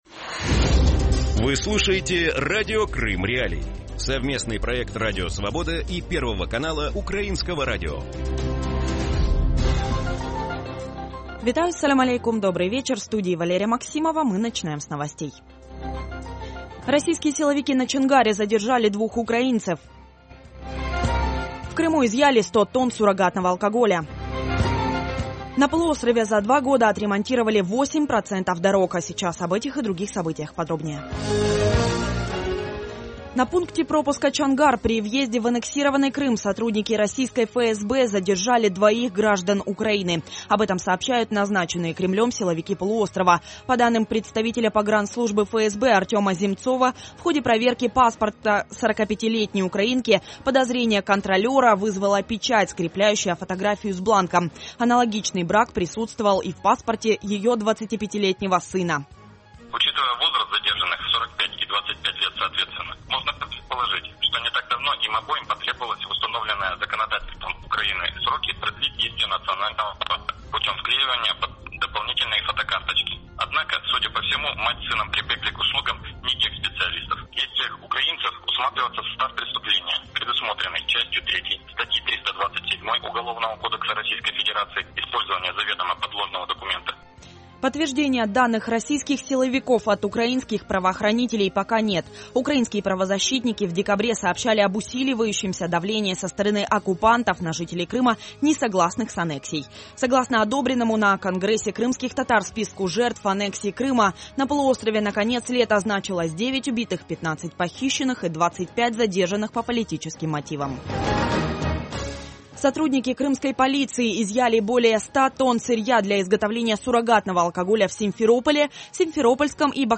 Вечерний выпуск новостей о событиях в Крыму. Все самое важное, что случилось к этому часу на полуострове.